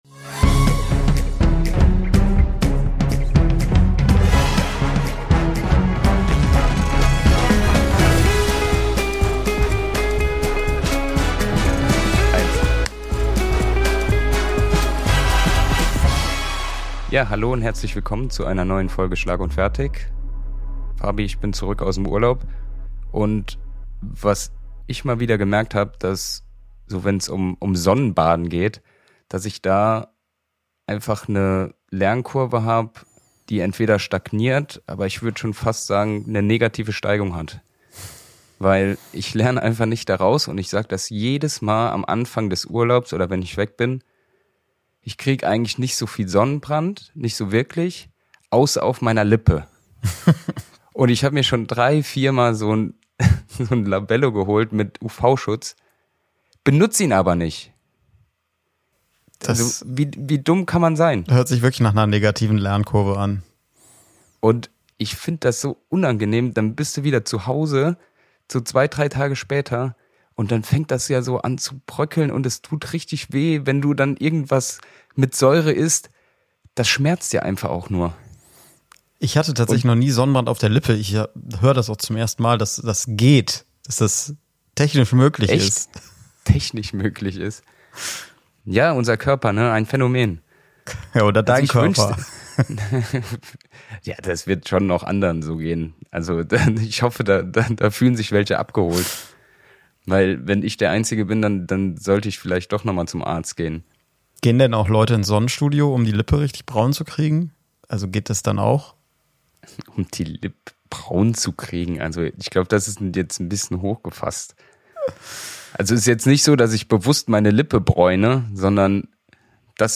Der ehemalige Fußball-Nationalspieler (43 Länderspiele) und der Comedian (heute-show) plaudern jede Woche aus dem Nähkästchen.